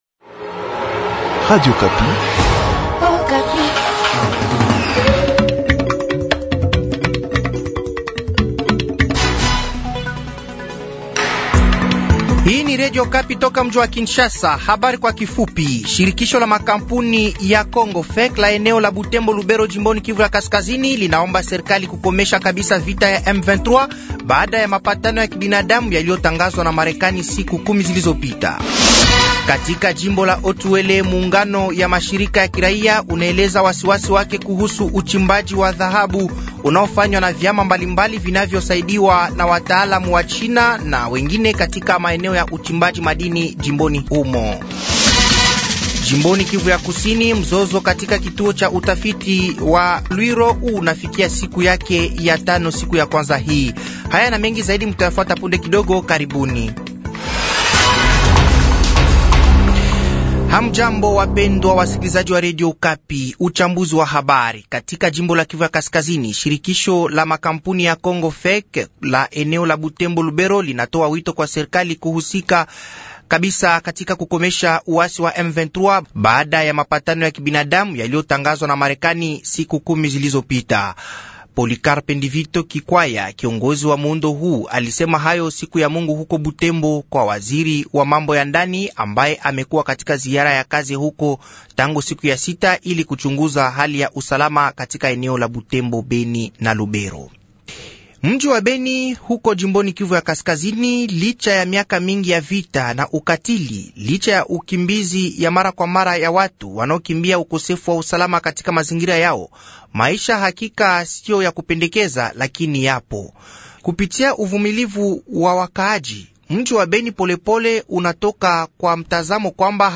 Habari za siku ya kwanza jioni tarehe 15/07/2024